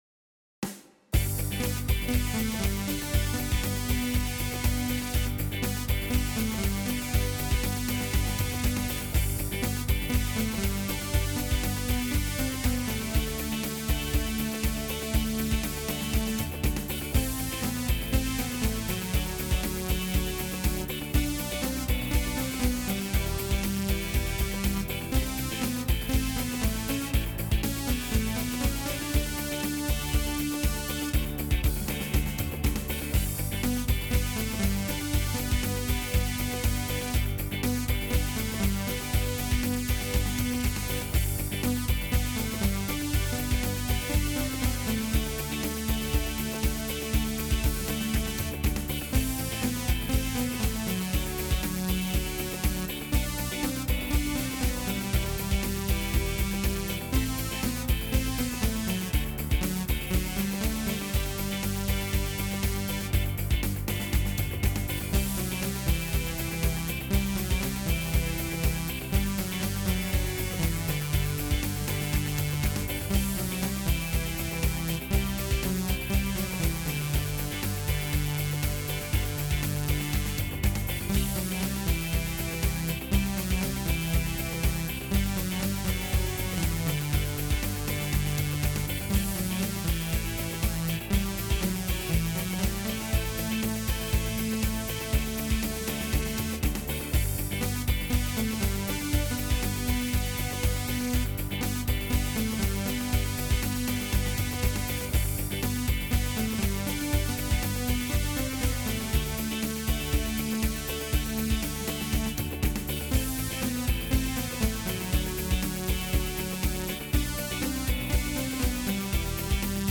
Pop , Rock